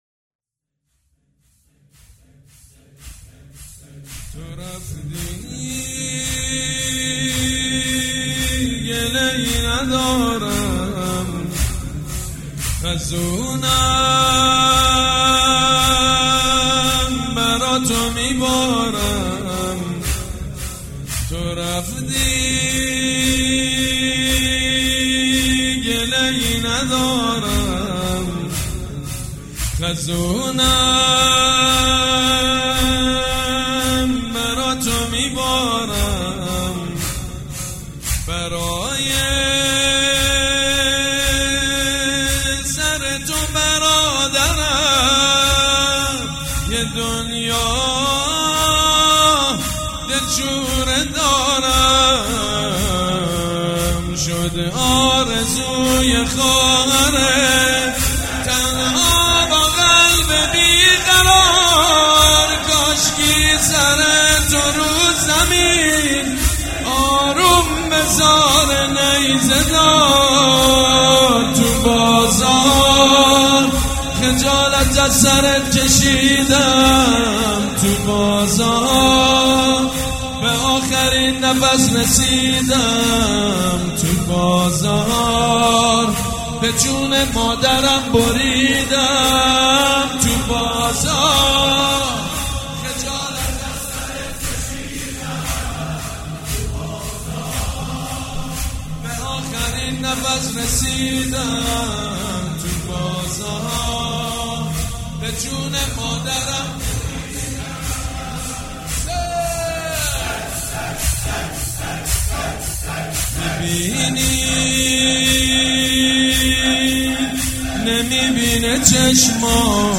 حاج سید مجید بنی فاطمه یکشنبه 12 فروردین 1397 هیئت ریحانه الحسین سلام الله علیها
سبک اثــر زمینه مداح حاج سید مجید بنی فاطمه
زمینه_تو رفتی.mp3